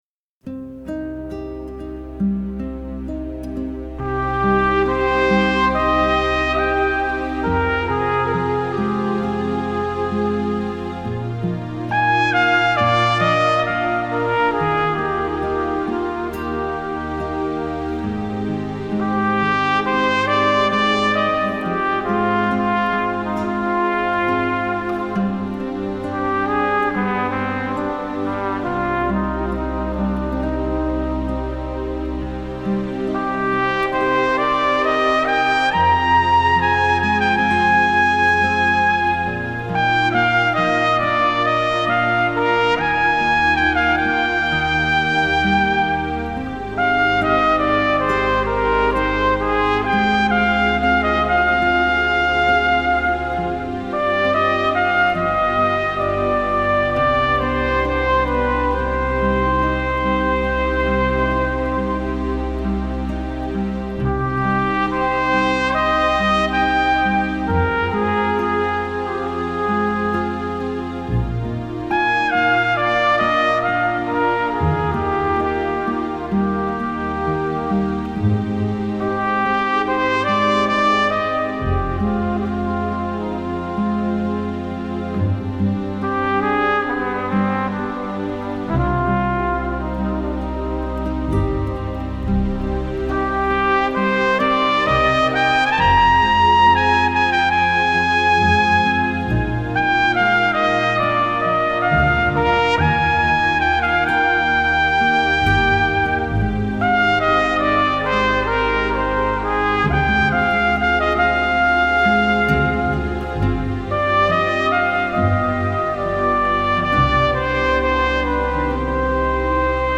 труба соло